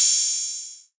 TM88 Open Hat.wav